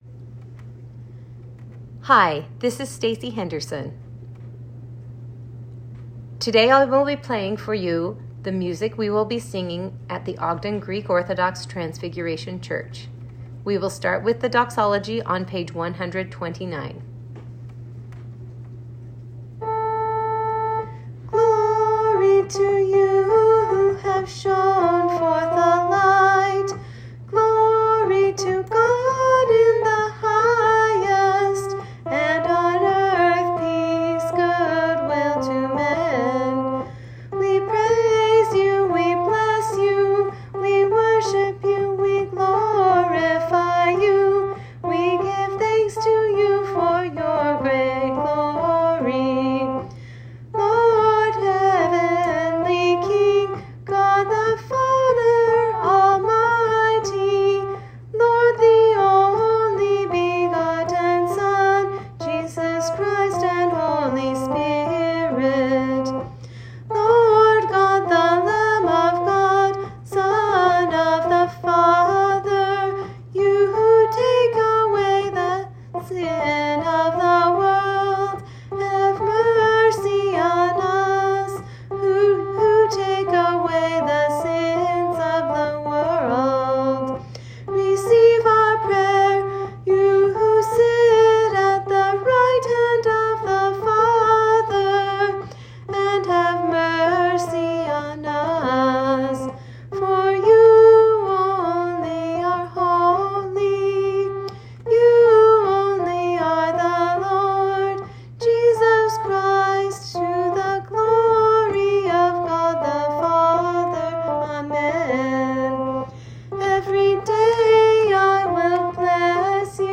Audio of our new music for the Divine Liturgy. Begining with the Doxology.
church_songs.m4a